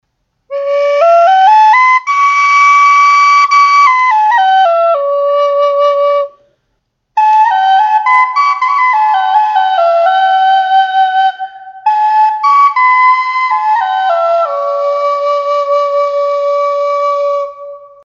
Western Cedar Native American Flutes
This is one of my favorite woods to make Native American Flutes out of because it is the most "mellow" sounding of all! 5 Hole Western Cedar Flues are also great Native American Flutes for beginners because the wood is so "forgiving" of the playing styles of new flute players.
$115 Key of High D